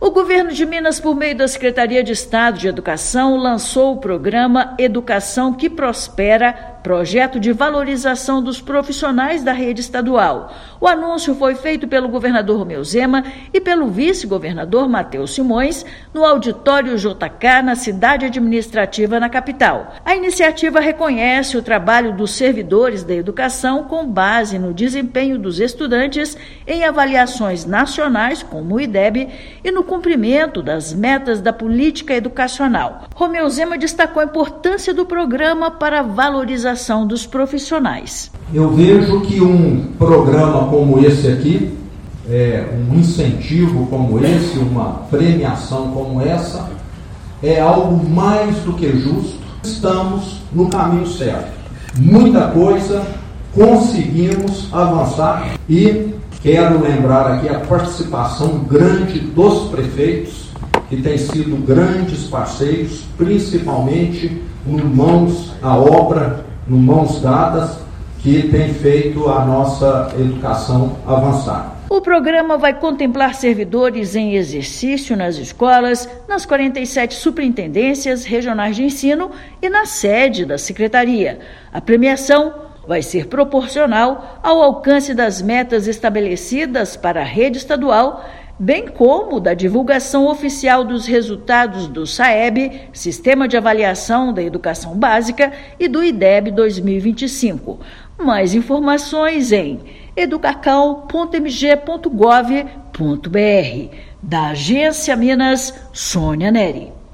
Iniciativa vai premiar servidores da rede estadual com base em metas de aprendizagem. Ouça matéria de rádio.